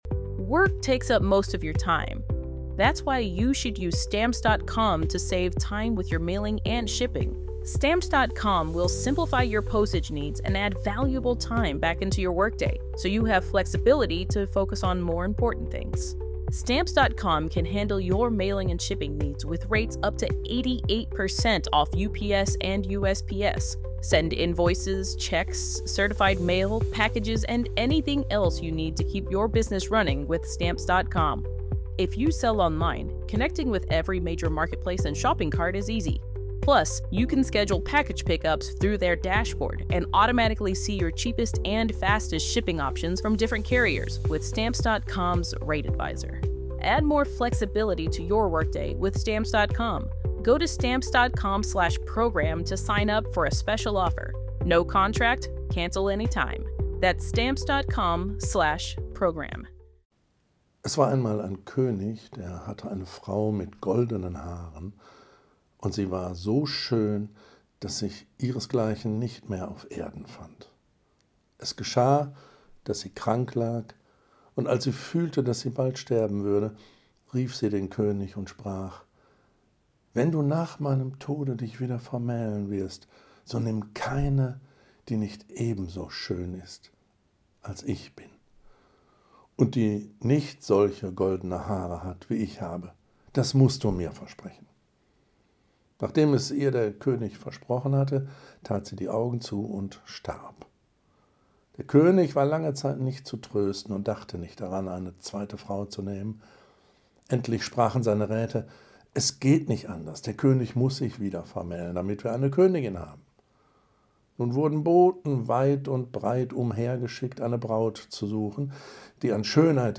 In diesem Podcast werden regelmäßig vorgelesene Novellen und Erzählungen präsentiert.
Die Texte wurden meist von Schauspielerinnen und Schauspielern eingelesen, und das, was zu hören ist, befindet sich auf professionellem Niveau.